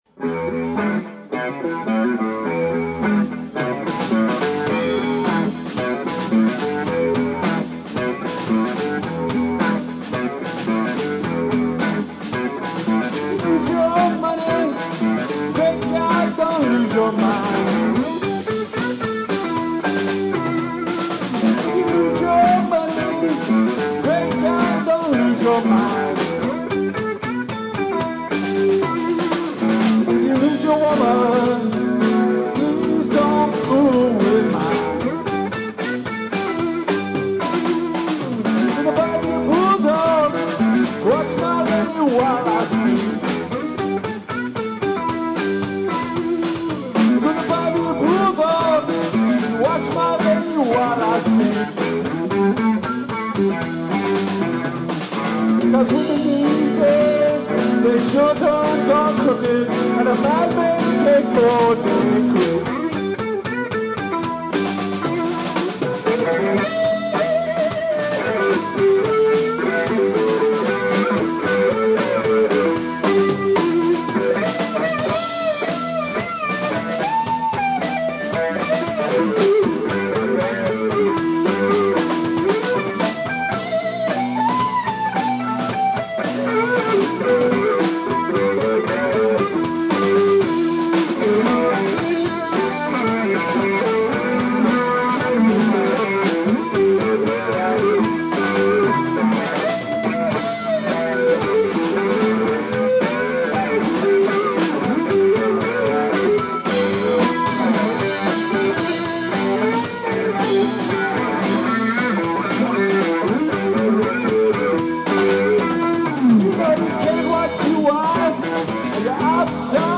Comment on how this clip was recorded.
More Tracks From Blue Lagoon - June '06